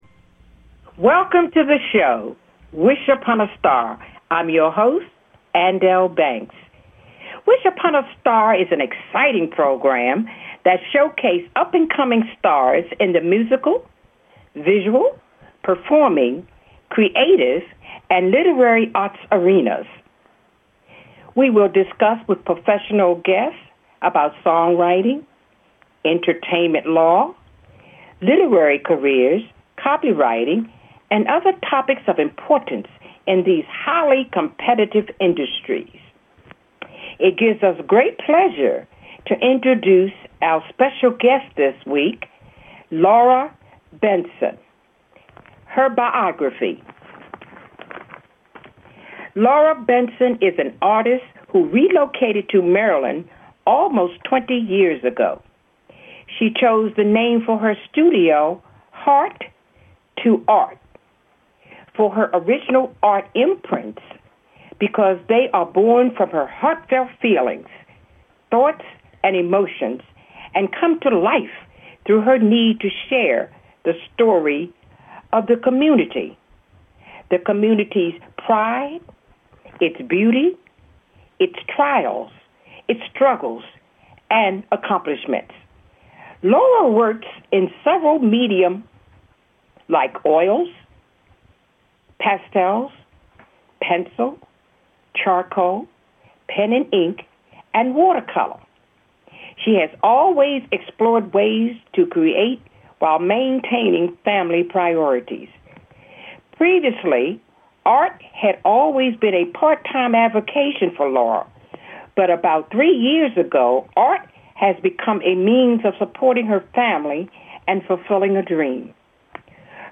"Wish Upon A Star" is a dynamic program dedicated to spotlighting talent in the arts arena. Conversations discussing the challenging elements of visual, musical, literary, painting, and the performing arts.